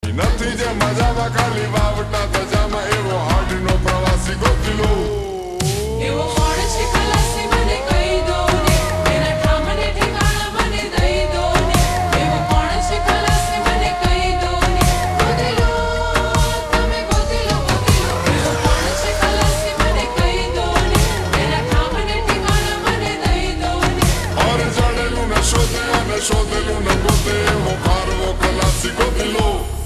Gujarati Songs
(Slowed + Reverb)